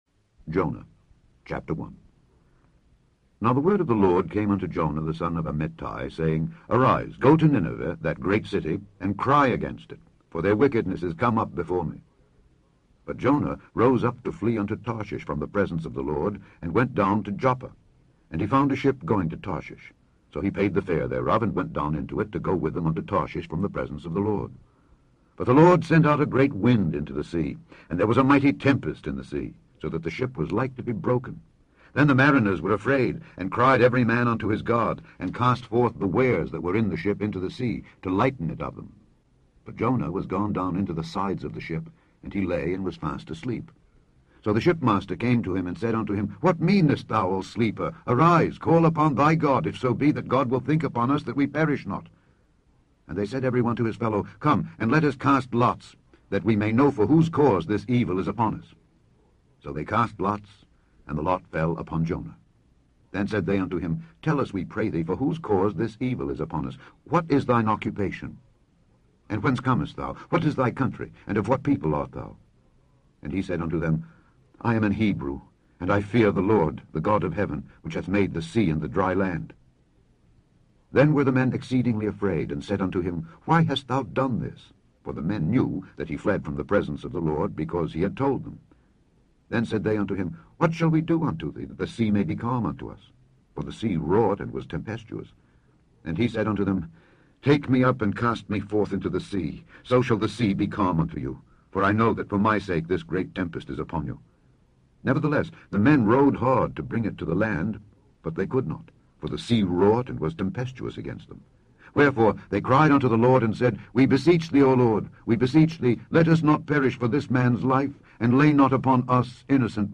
Scourby Audio Bible